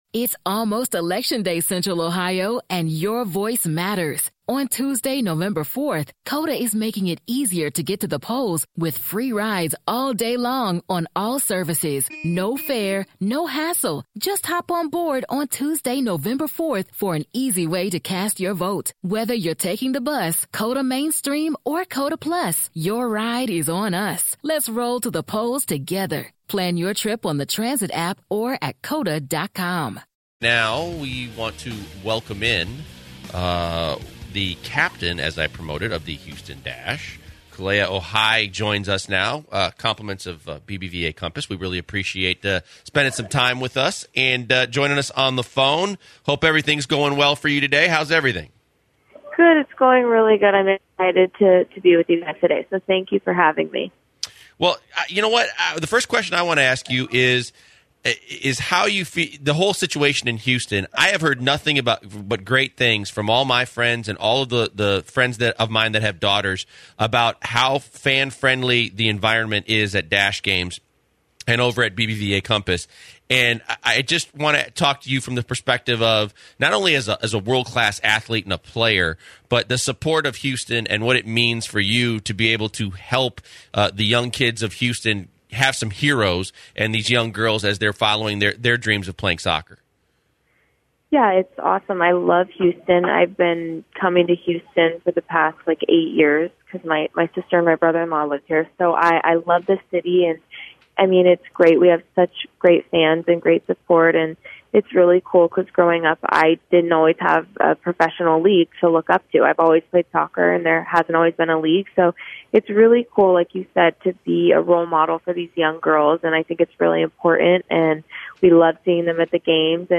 Special guest Houston Dash Captain Kealia Ohai talks about her being legally blind in one eye and how she overcomes it. She also explains how it is to be a role model and how much it meant for her to be called up to the National Team.